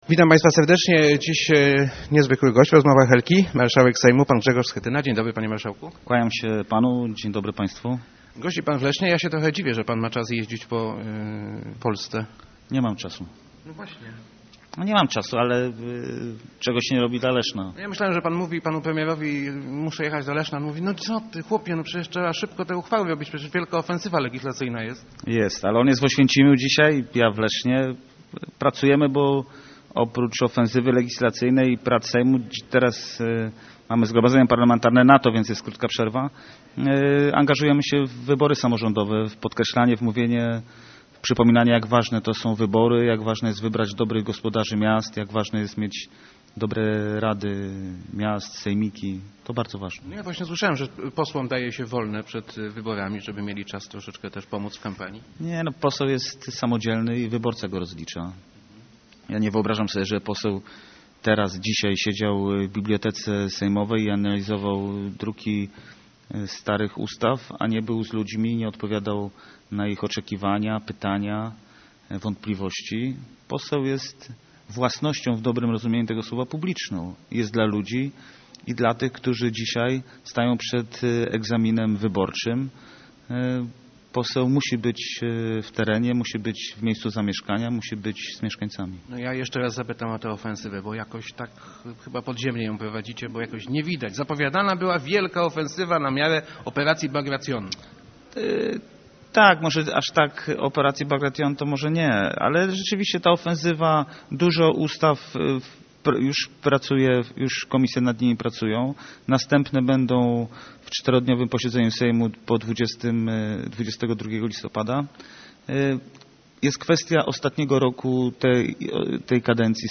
Marszałek Sejmu w Radiu Elka